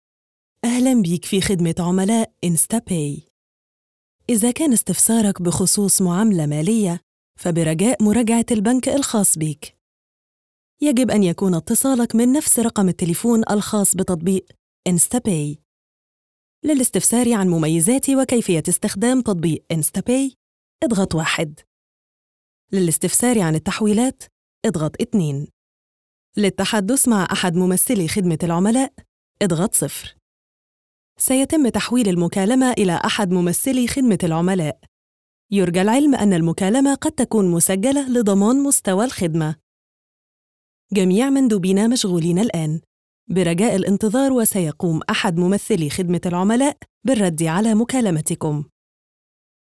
Interactive Telephony Response
Professional Arabic Voice-Over Service
Response Telephony Voice Female Ivr